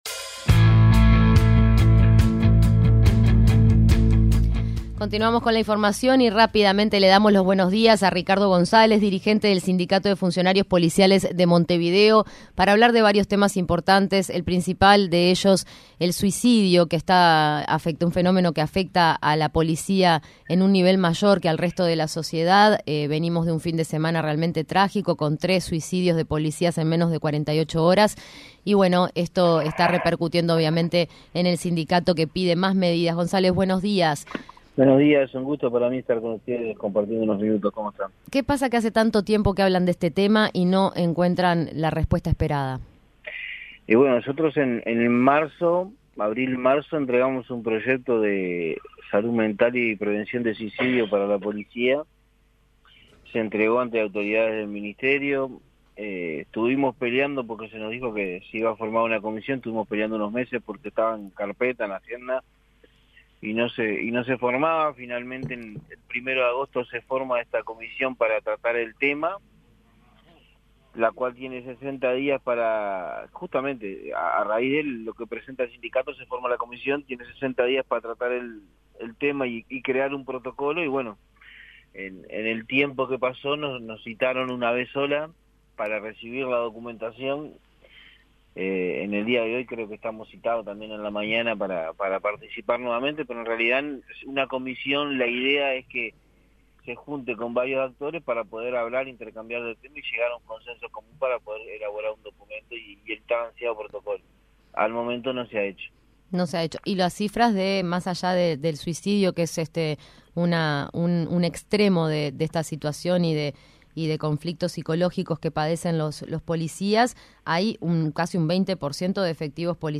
fue entrevistado en 970 Noticias Primera Edición